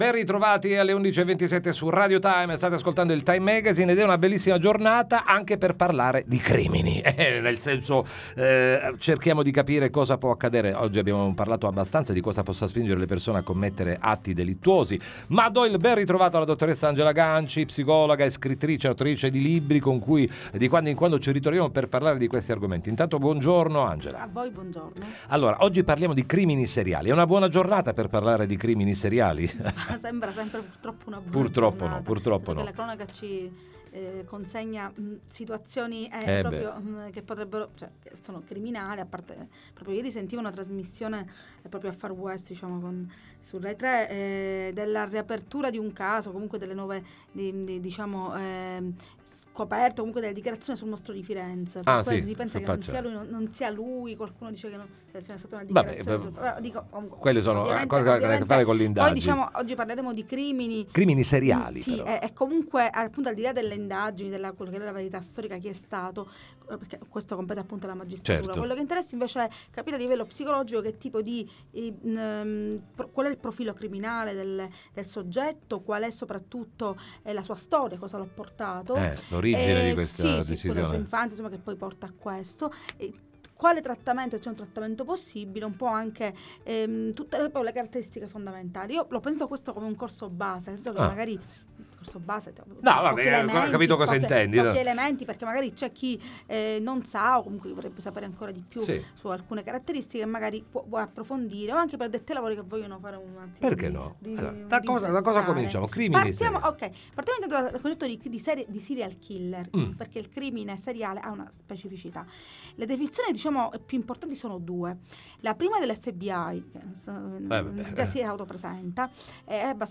parliamo con lei nei nostri studi